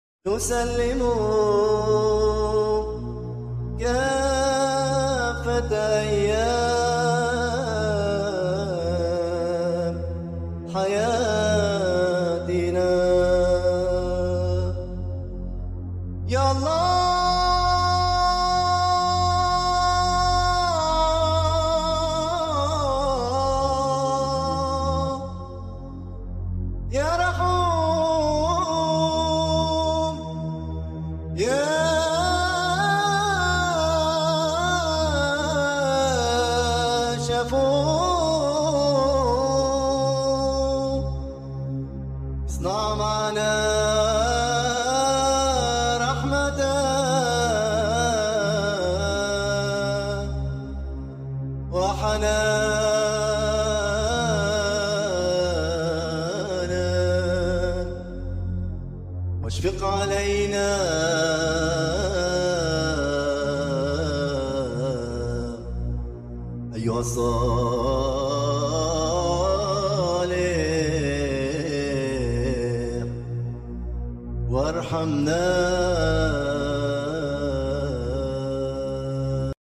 Orthodox christian recitation in Arabic sound effects free download